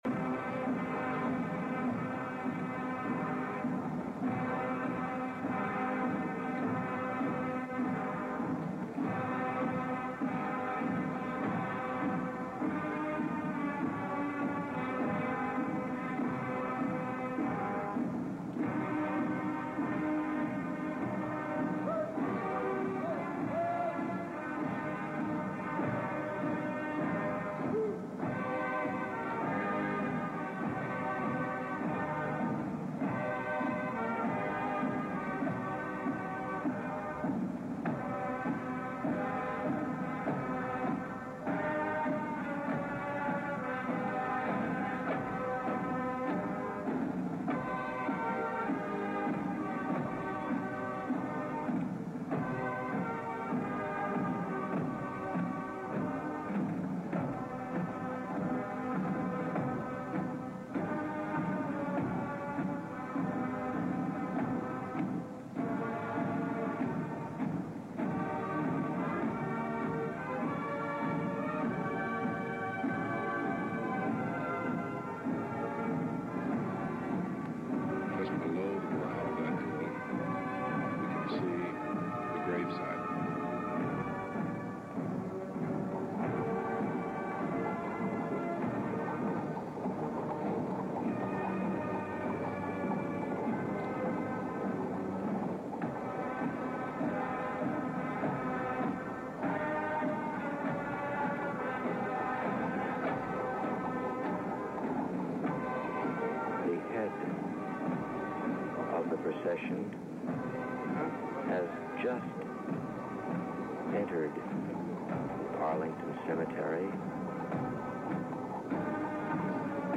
Funeral of Former American President John F. Kennedy 1963